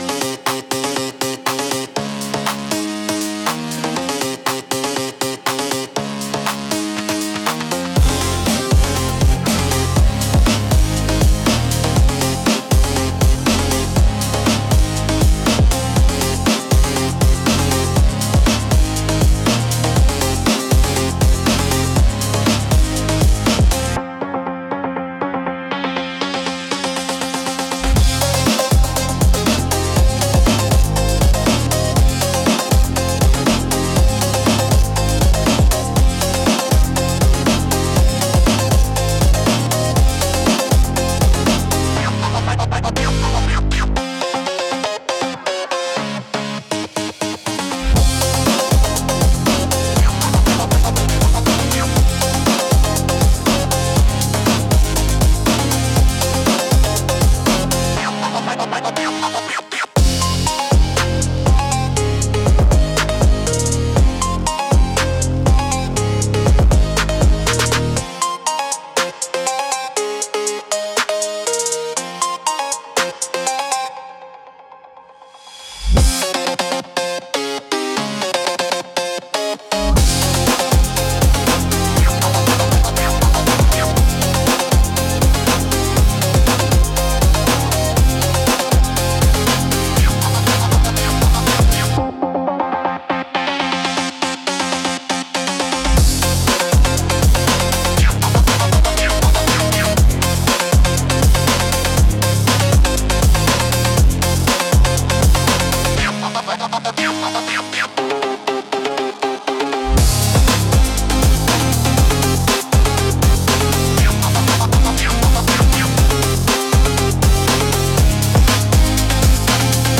122 BPM
Trap